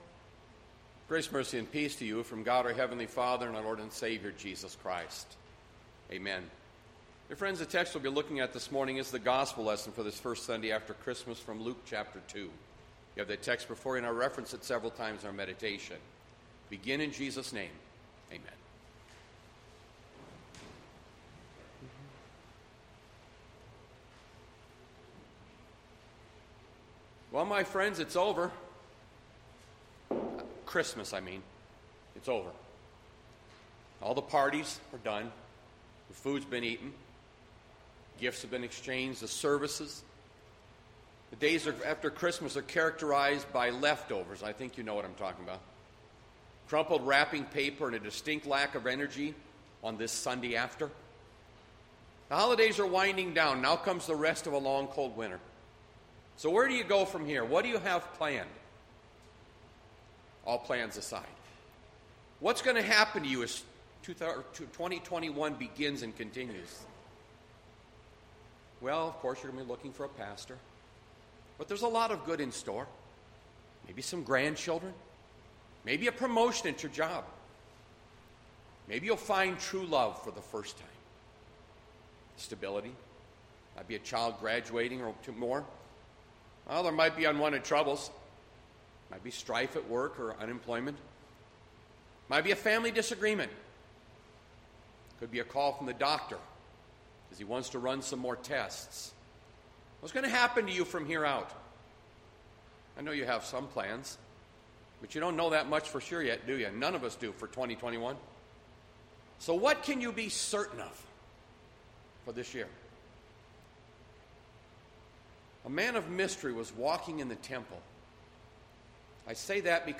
Bethlehem Lutheran Church, Mason City, Iowa - Sermon Archive Dec 27, 2020